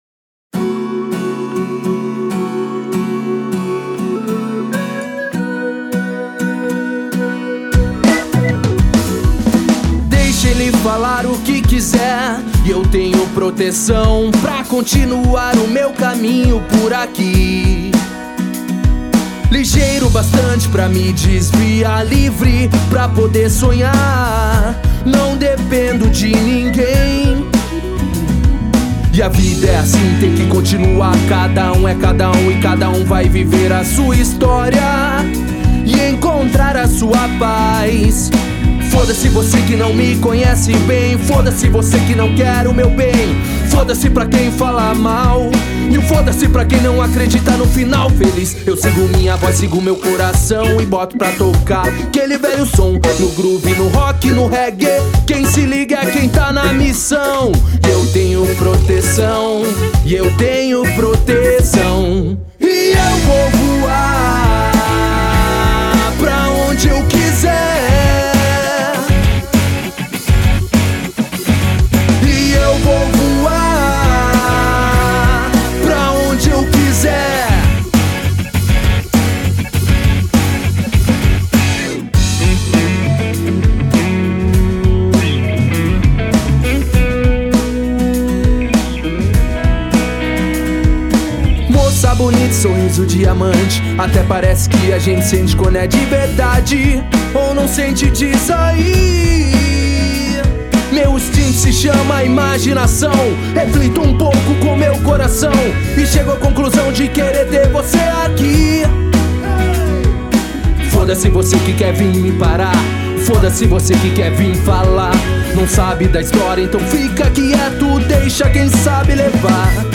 EstiloReggae